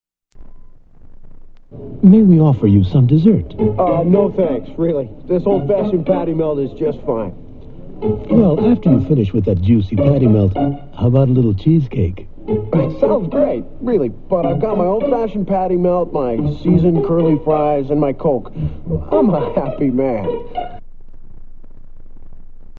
• Audiocassette